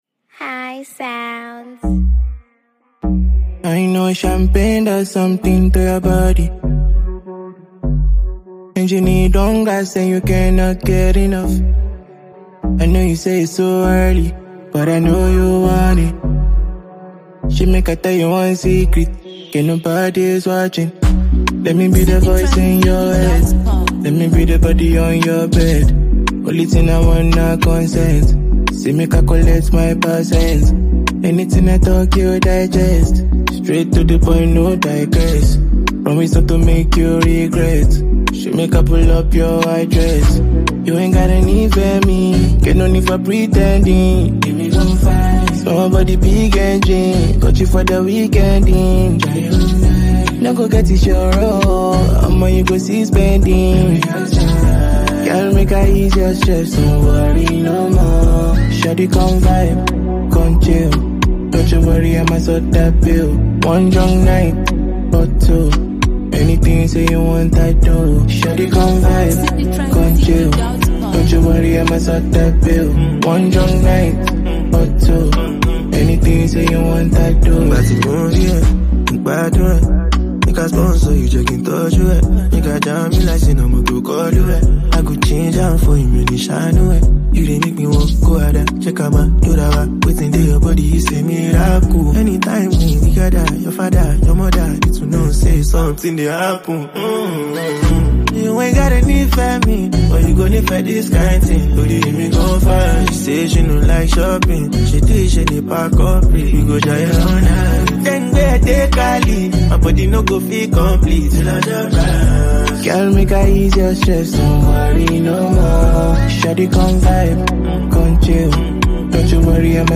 and sun-soaked energy